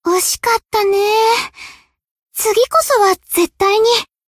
贡献 ） 分类:蔚蓝档案语音 协议:Copyright 您不可以覆盖此文件。
BA_V_Nonomi_Tactic_Defeat_2.ogg